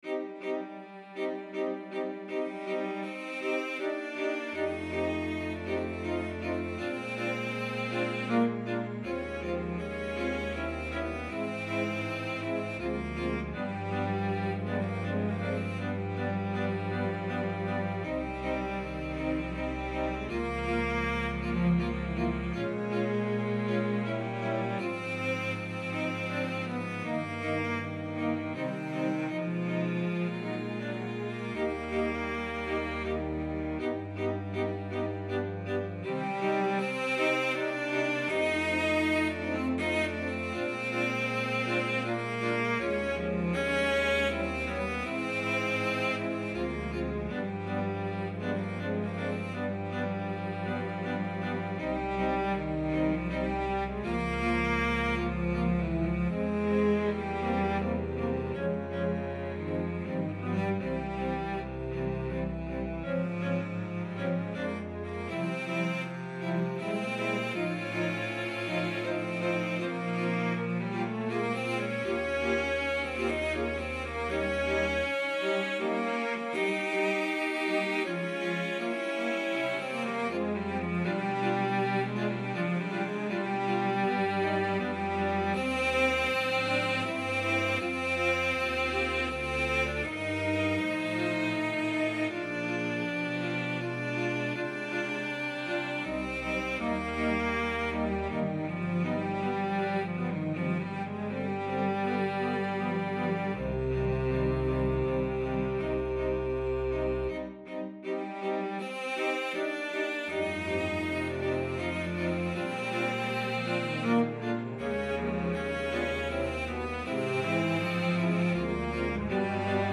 Digital Recording of an arrangement
for Lower String Ensemble